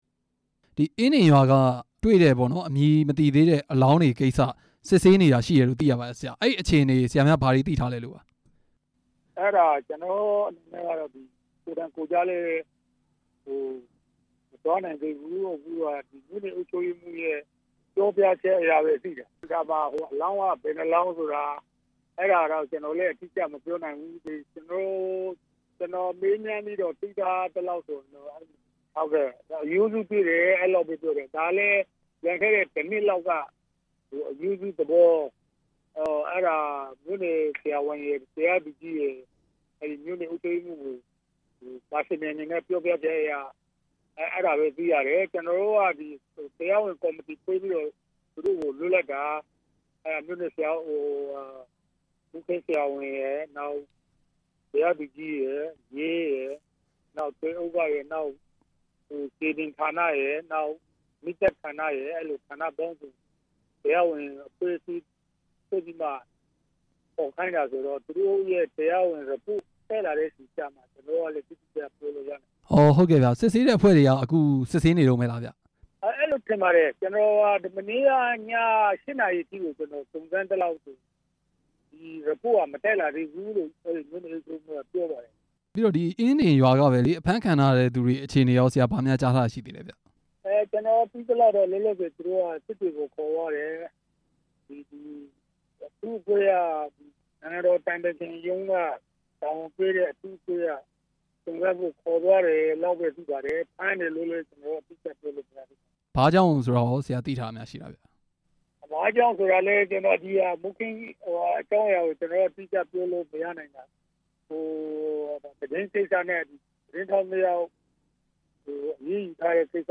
အင်းဒင်ရွာက အလောင်းတွေကို စစ်ဆေးနေတဲ့ အခြေအနေ မေးမြန်းချက်